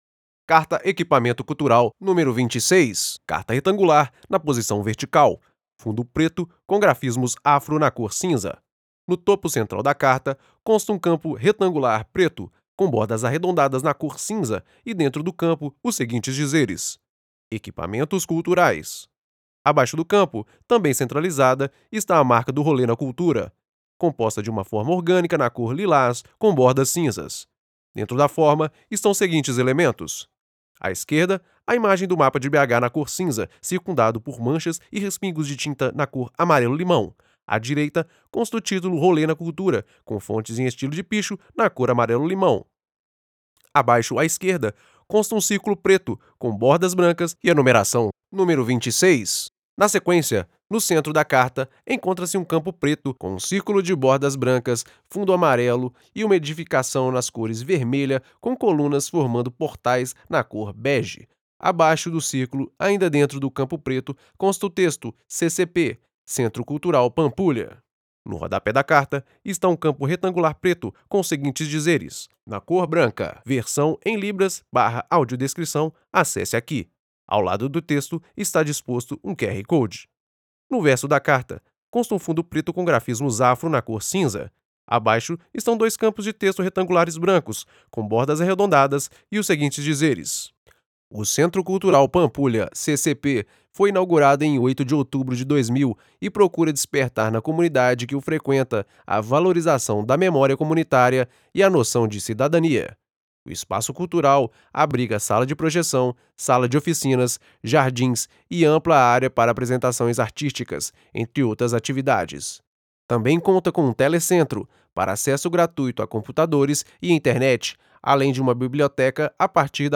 Audiodescrição CCP